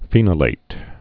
(fēnə-lāt)